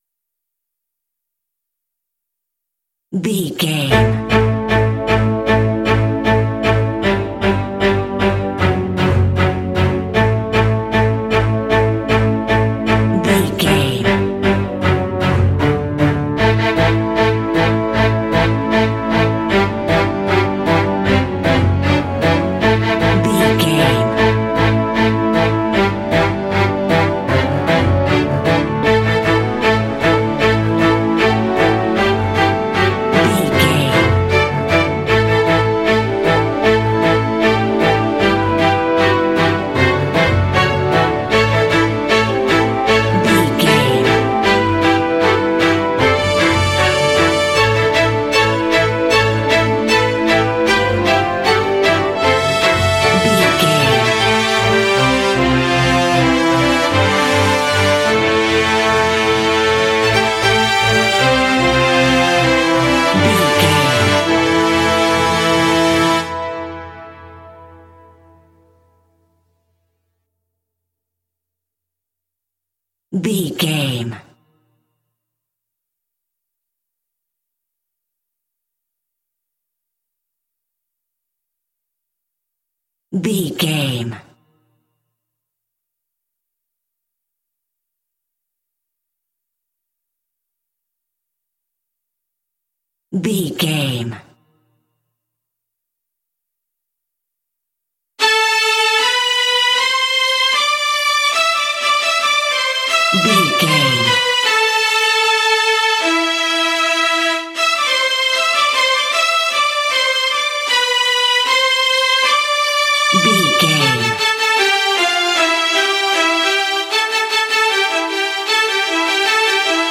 Epic / Action
Dorian
dark
energetic
aggressive
brass
cinematic
symphonic rock